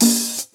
Openhat_ghs.wav